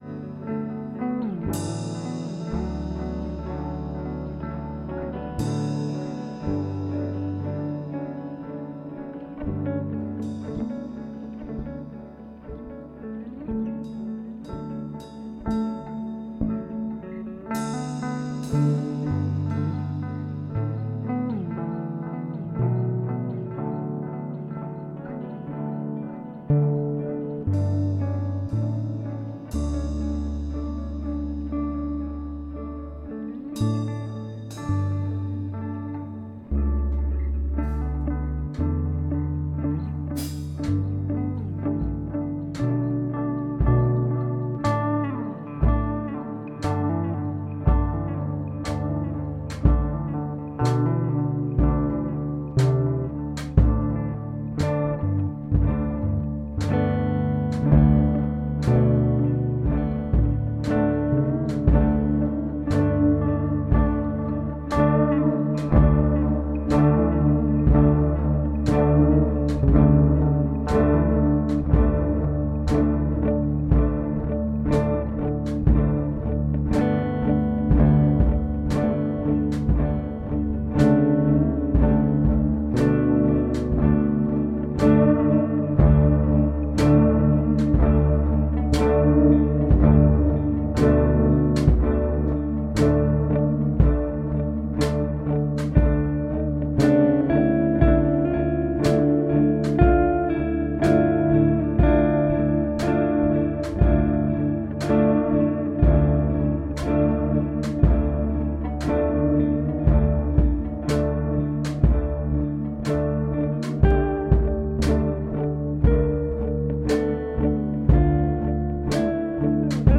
guitar, drums, bass. 3 bests
I can safely say the first two are clean.
guitarcore_1_of_3_mix.mp3